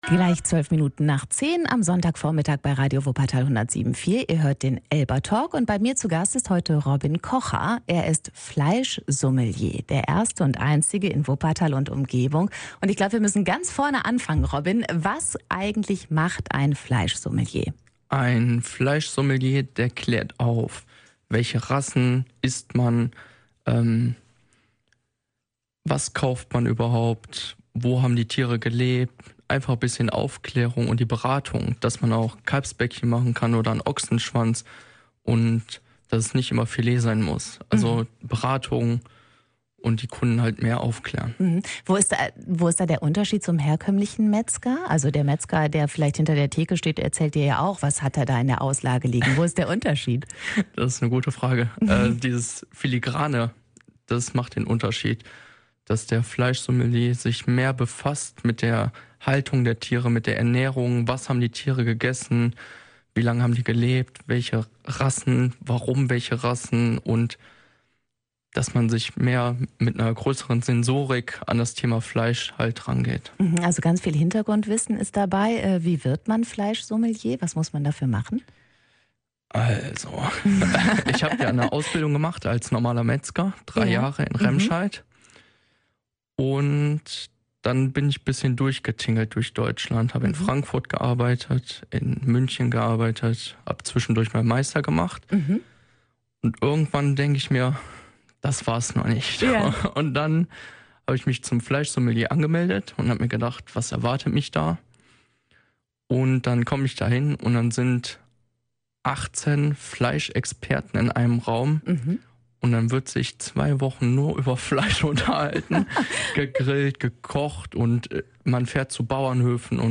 ELBA-Talk